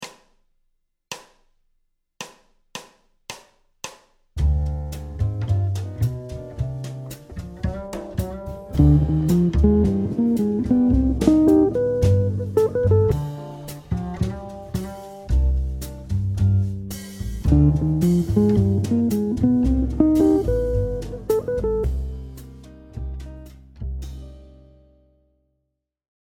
Motif Jazz D1 : Arpège 7 ascendant
Phrase 16 – Cadence ii V7 i en mineur
phrase-16-ii-V7-i-en-min.mp3